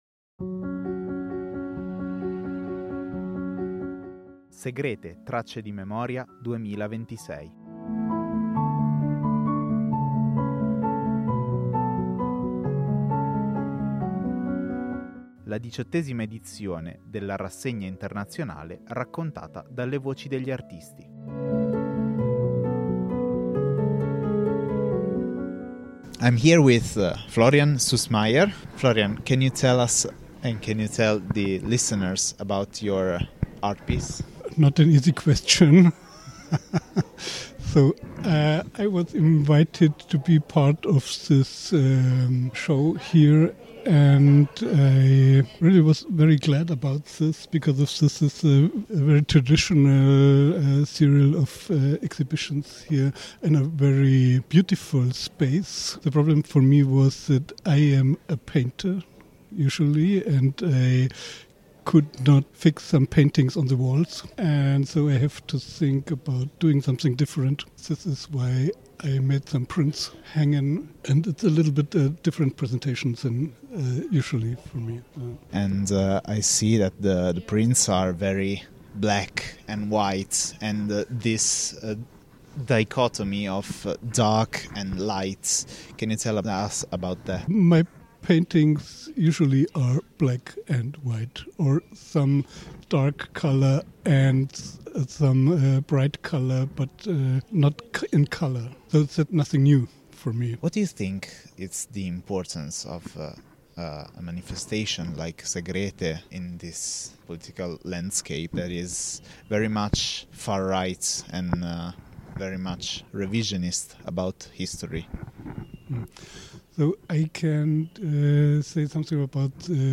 intervista e montaggio